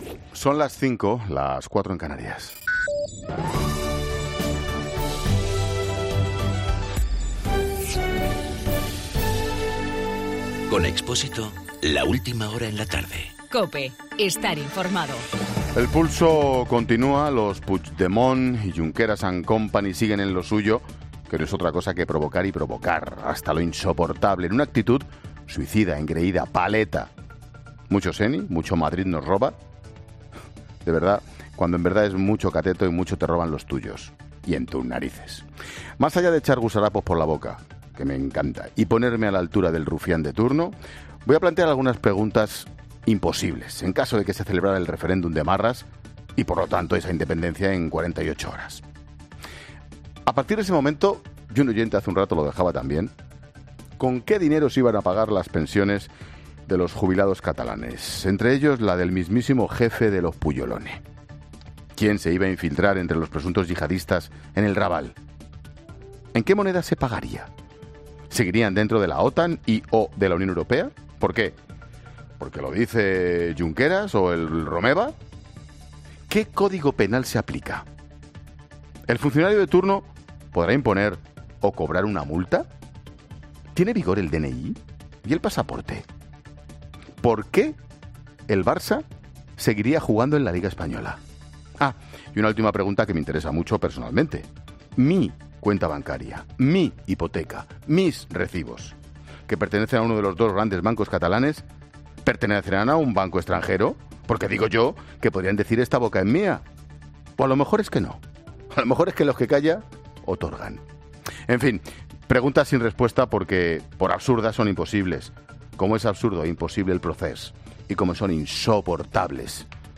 Monólogo de Expósito
En el monólogo de las 17h, Ángel Expósito habla sobre las dudas que traería la aplicación de la ley del referéndum catalán.